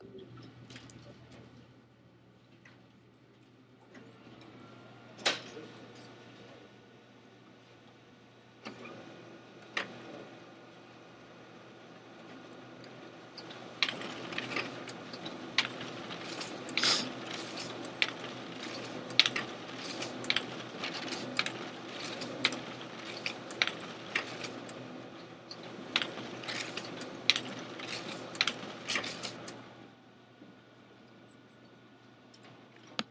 March 21, Hammer computer lab, 2:02 pm
Printer.mp3